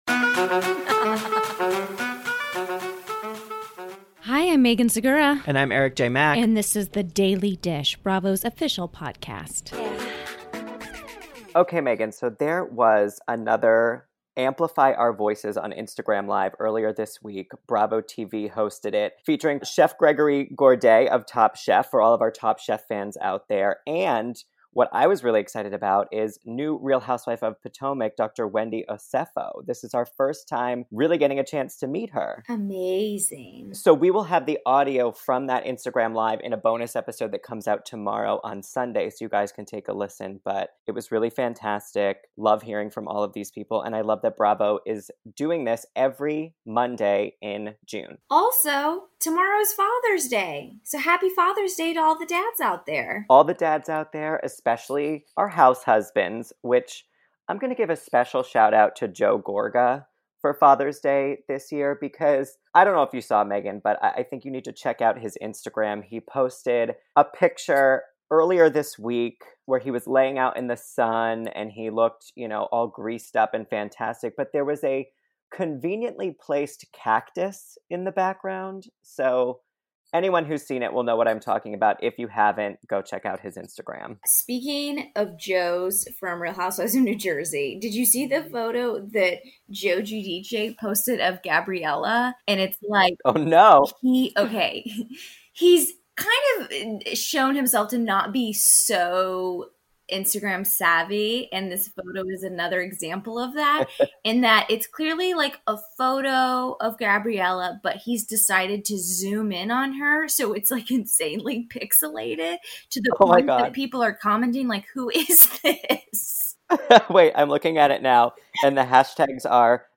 Plus, The Real Housewives of Potomac’s Gizelle Bryant calls in to give us an update on her relationship with partner Jamal Bryant, reveal how her daughters are following in their parents’ footsteps as activists, and tells us everything we can expect from new House